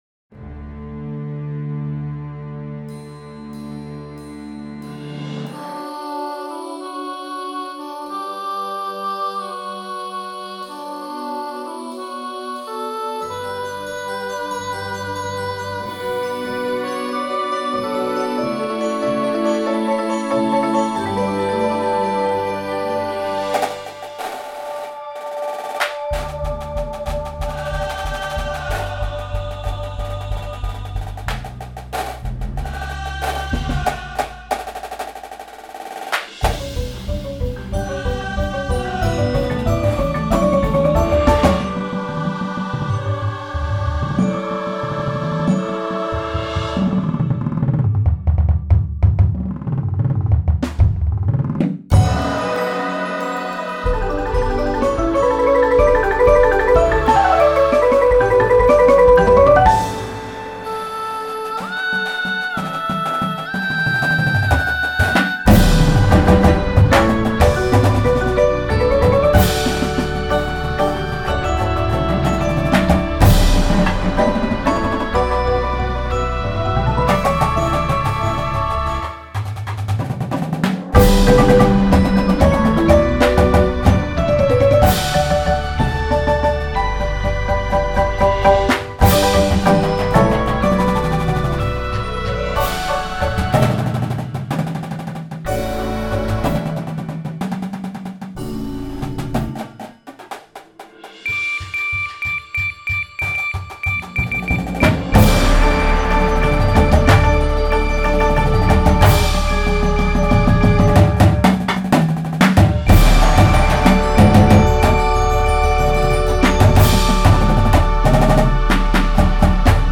Indoor Percussion Shows
Front Ensemble